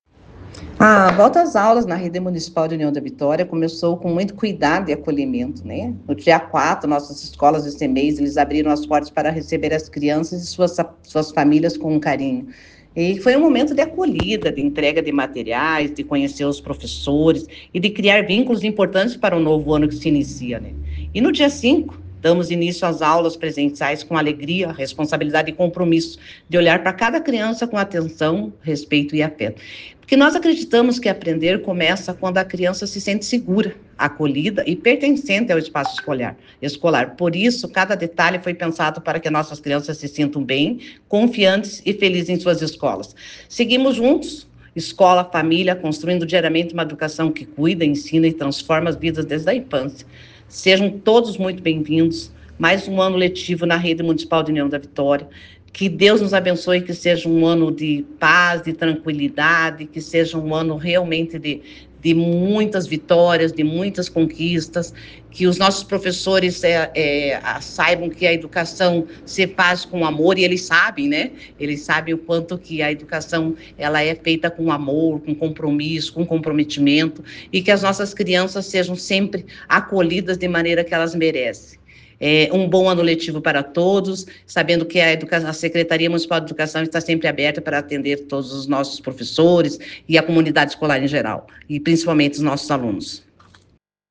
A secretária de educação do Município, professora Solange Garcia Behrens, fala sobre o retorno//